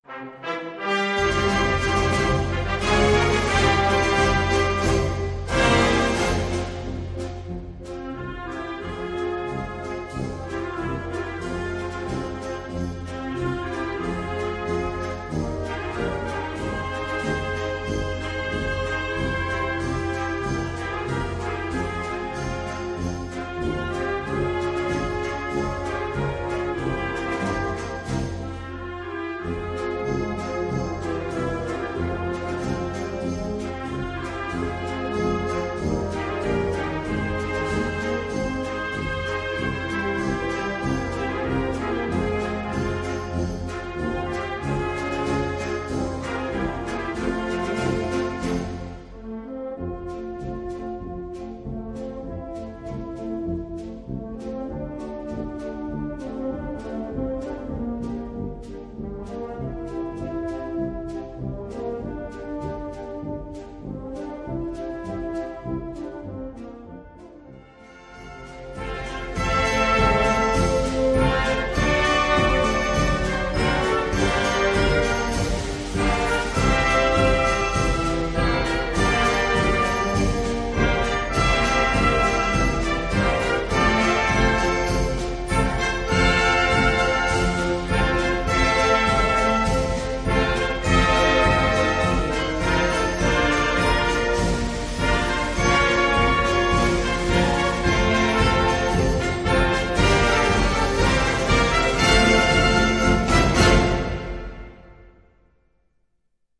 Gattung: Paso doble
Besetzung: Blasorchester